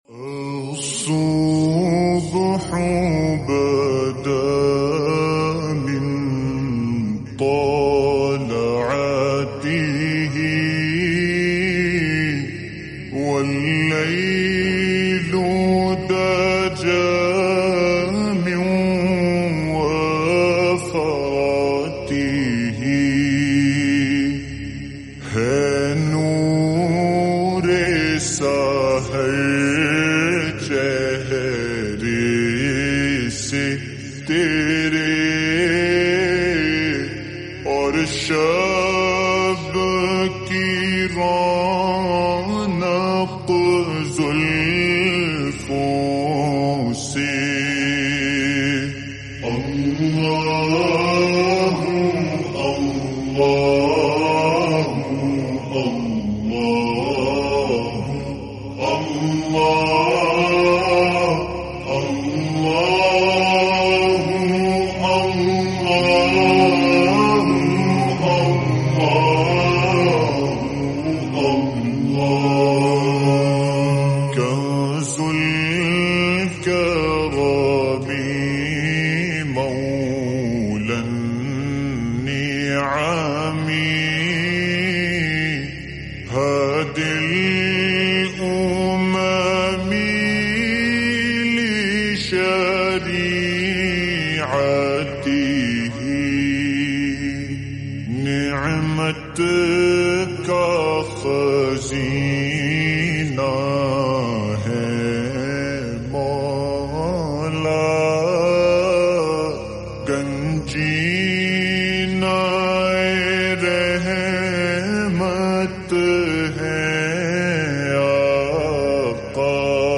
SLOWED+REWERB